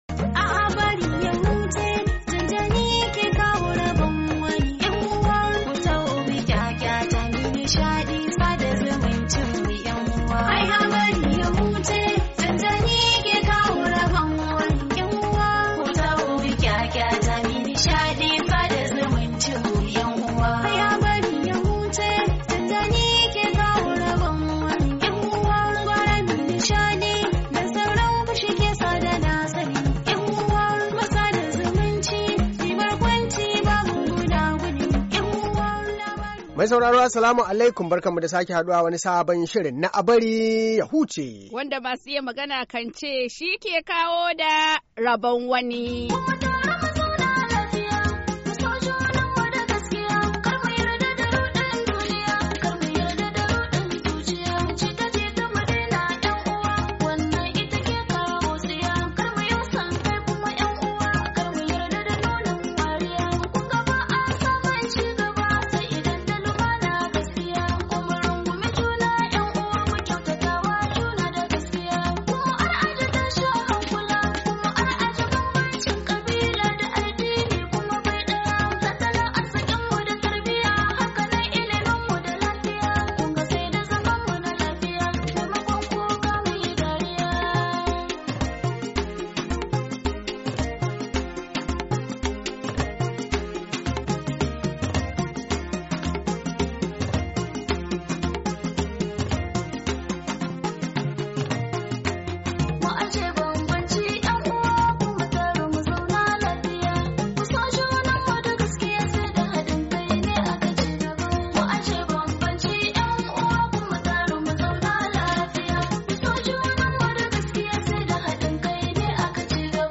Saurari shirinmu na "A Bari Ya Huce" na ranar Asabar 12, Mayu 2018 domin jin labaran ban dariya na wannan makon da kuma irin kade kade da gaishe gaishe da suka sami shiga cikin shirin.